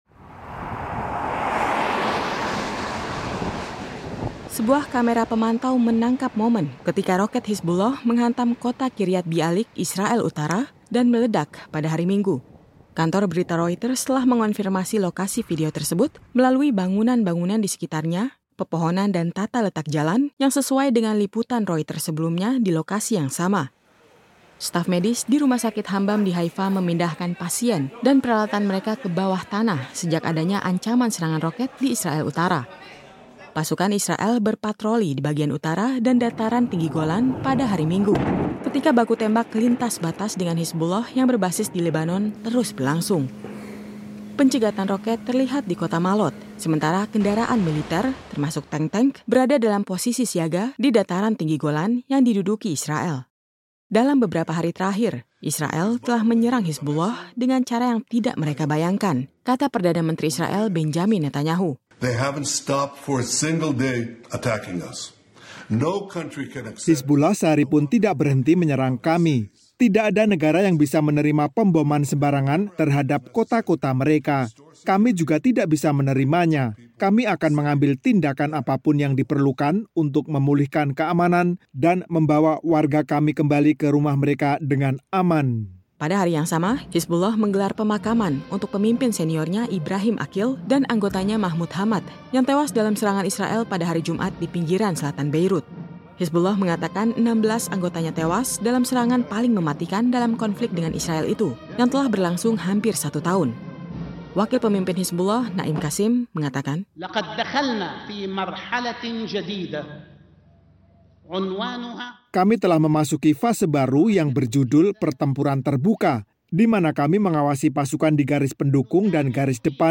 Sebuah kamera pemantau menangkap momen ketika roket Hizbullah menghantam Kiryat Bialik, Israel utara, dan meledak pada hari Minggu (22/9).
Pencegatan roket terlihat di kota Maalot, sementara kendaraan militer, termasuk tank-tank, berada dalam posisi siaga di Dataran Tinggi Golan yang diduduki Israel.